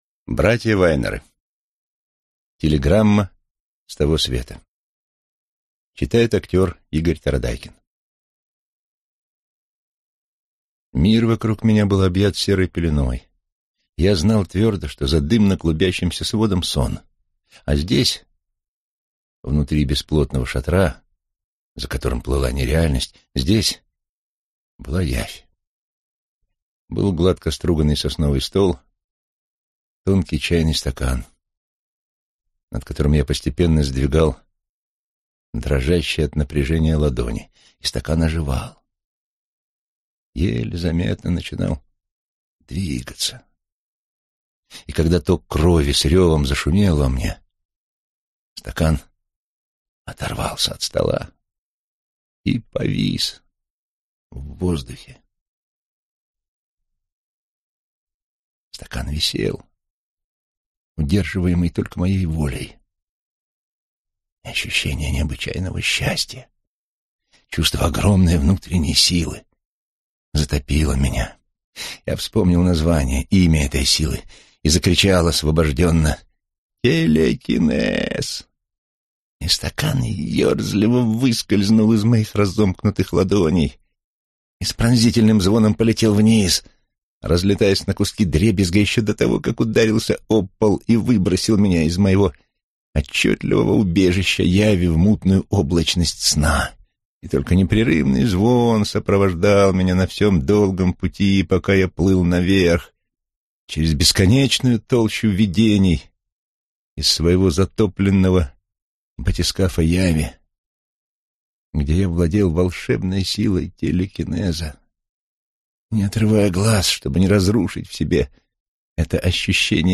Аудиокнига Телеграмма с того света | Библиотека аудиокниг